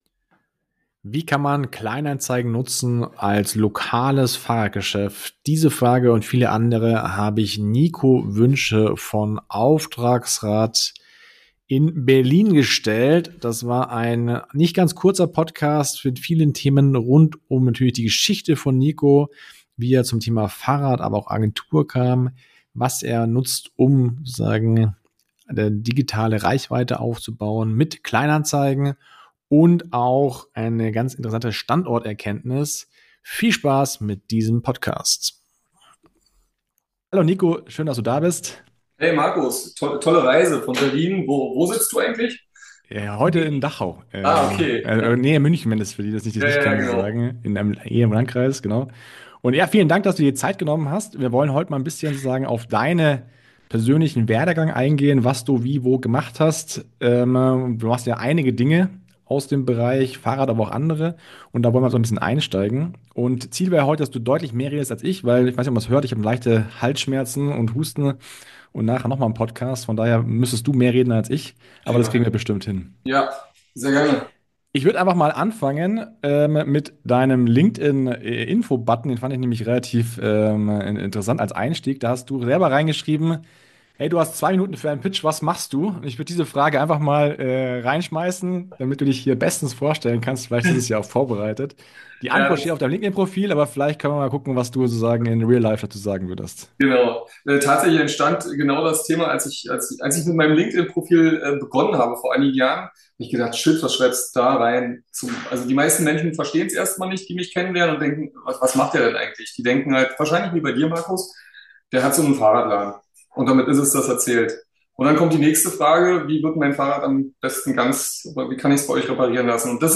Kleinanzeigen für dein lokales Unternehmen? Interview